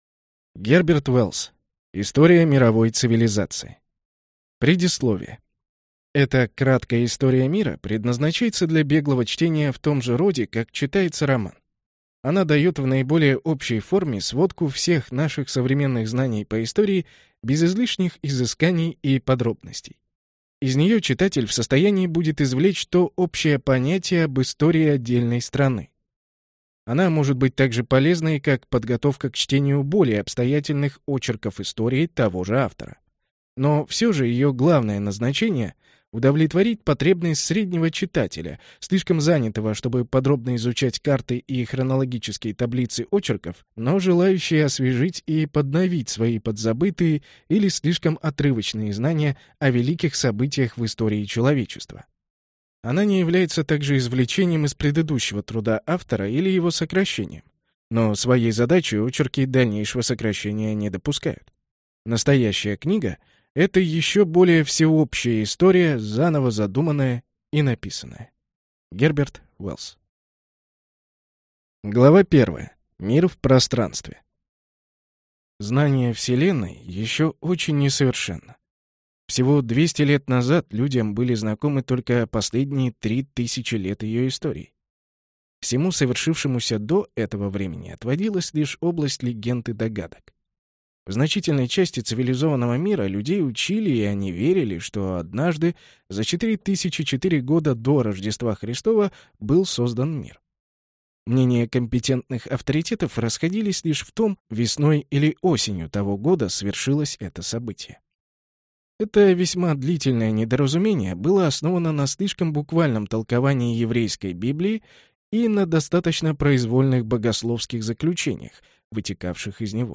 Аудиокнига История мировой цивилизации | Библиотека аудиокниг